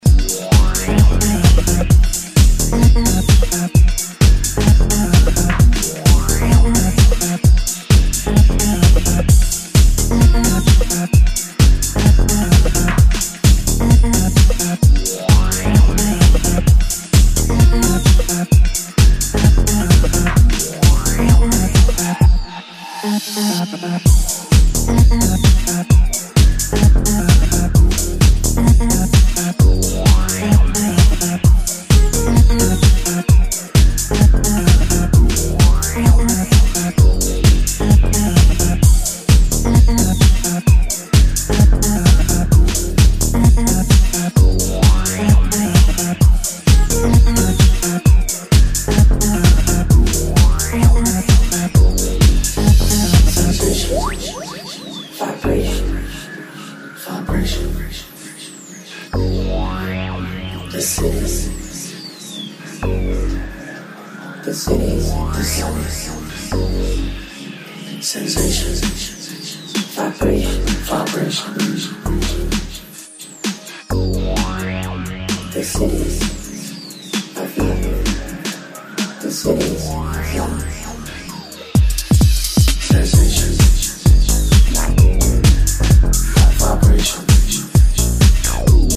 more electro-tinged territories than his previous material
modern house music